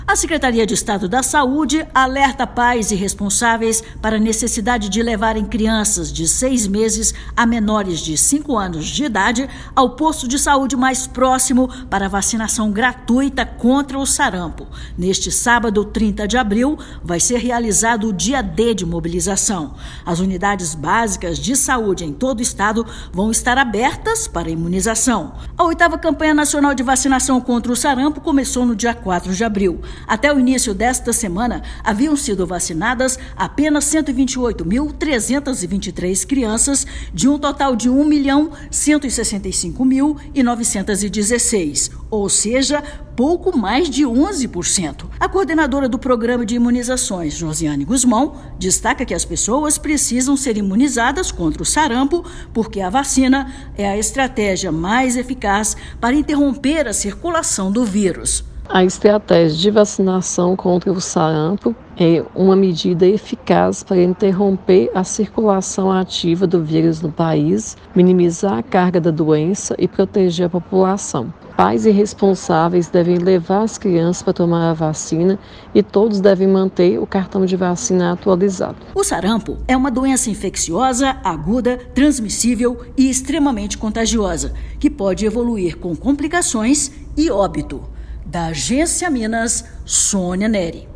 [RÁDIO] Saúde alerta para necessidade de vacinar contra o sarampo e faz Dia D de Mobilização neste sábado (30/4)
A Secretaria de Estado de Saúde de Minas Gerais (SES-MG) alerta pais e responsáveis para a necessidade de levarem crianças de seis meses a menores de cinco anos de idade ao posto de saúde mais próximo para vacinação gratuita contra o sarampo. Ouça matéria de rádio.